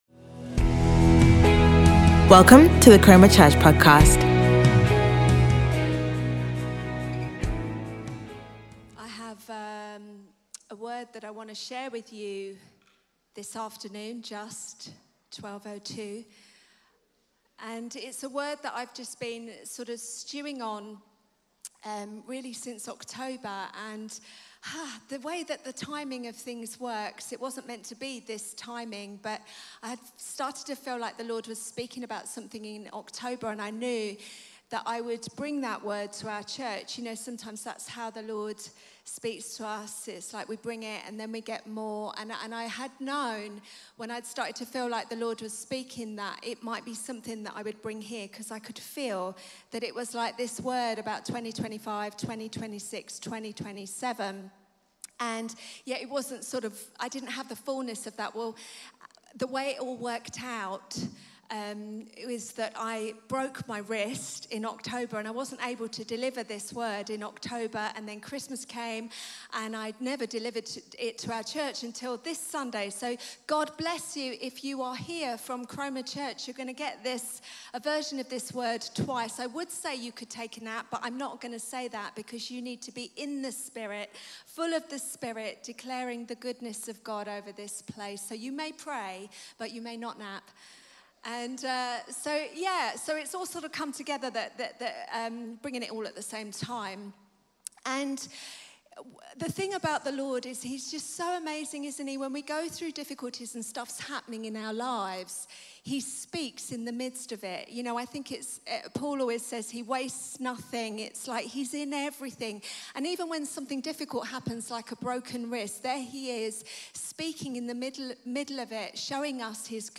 Hello Chroma Family, here is session 1 of our LG: The Word and The Spirit conference